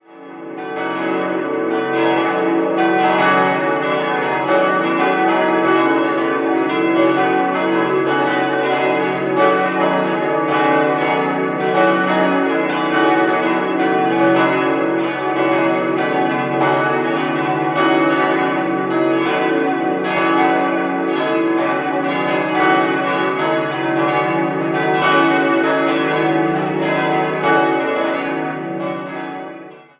5-stimmiges Geläut: cis'-dis'-fis'-gis'-h'